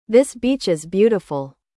Exemplos perigosos com /iː/ (longo) e /ɪ/ (curto):
2. This beach is beautiful. (ðɪs biːtʃ ɪz ˈbjuːtɪfəl)